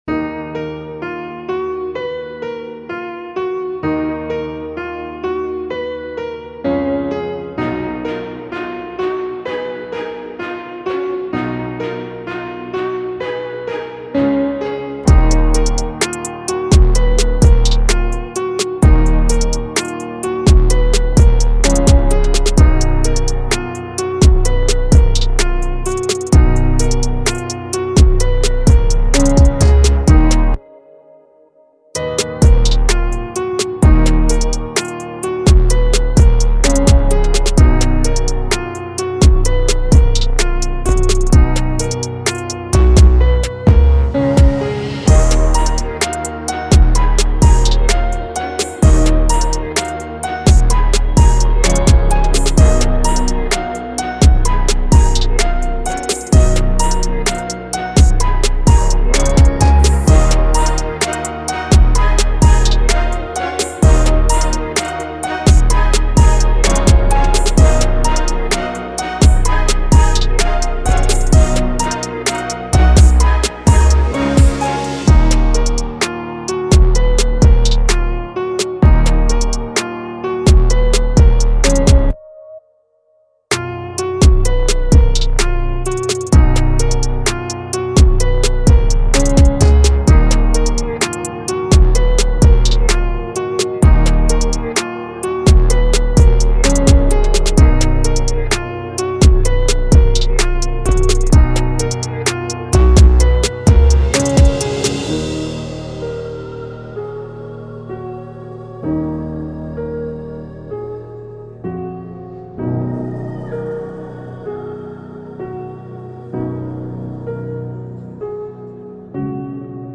official instrumental
Trap Instrumentals